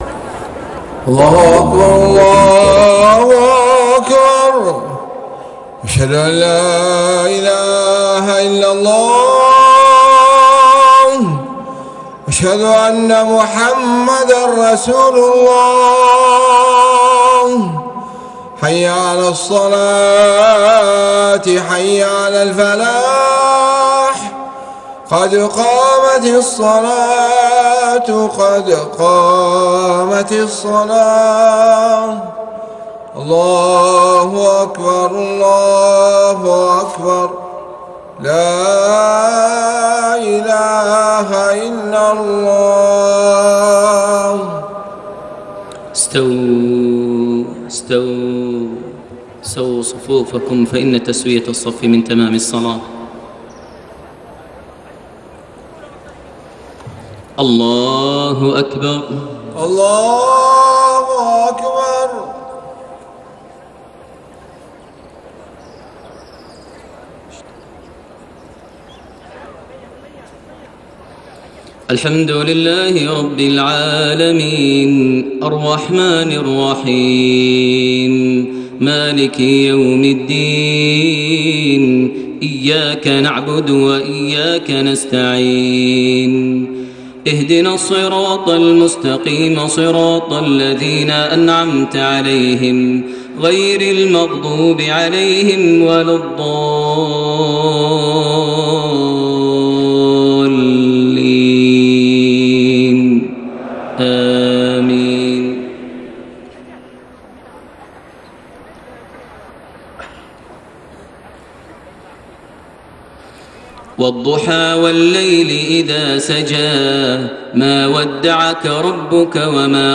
صلاة المغرب 1 شوال 1432هـ سورتي الضحى و الكوثر > 1432 هـ > الفروض - تلاوات ماهر المعيقلي